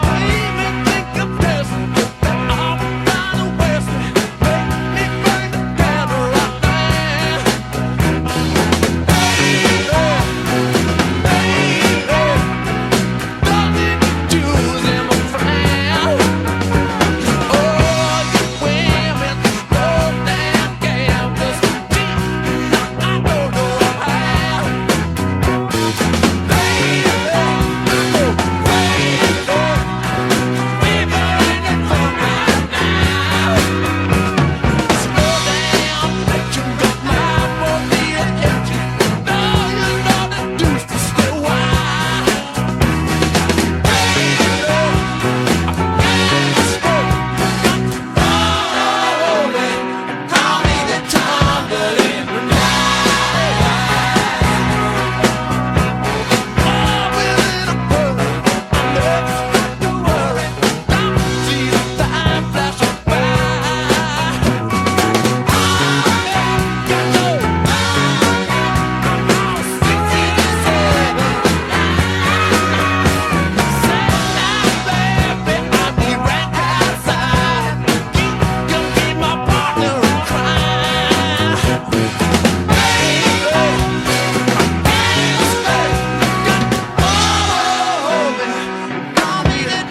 POLISH HARDCORE